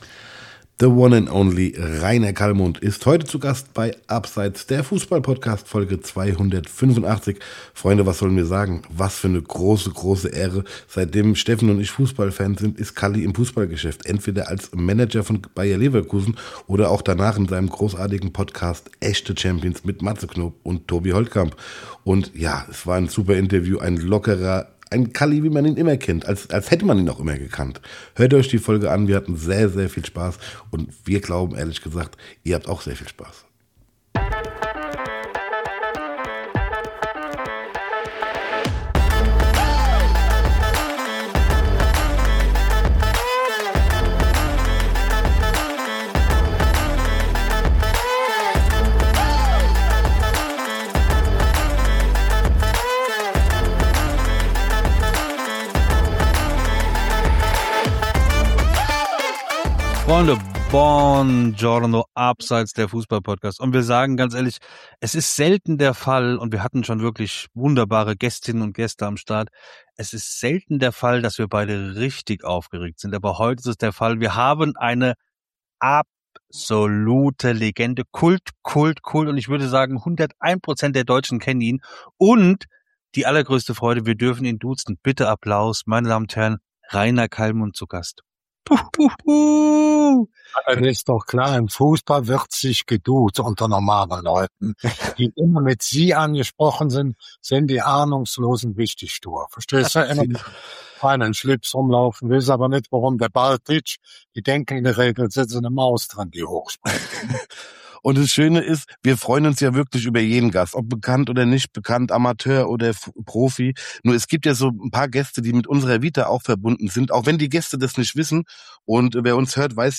Wir haben heute eine Legende des deutschen Fußballs zu Gast: Reiner Calmund! Wir reden mit Calli über die aktuelle Bundesliga, er erzählt über seinen Podcast "Echte Champions" und er berichtet eine spannende Anekdote über den Mauerfall - Viel Spaß!...